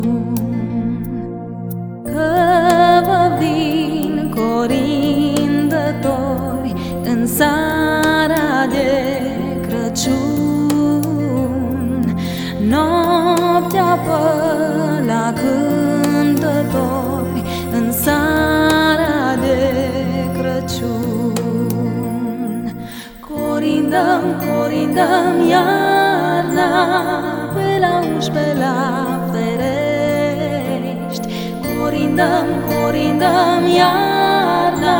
# Christian & Gospel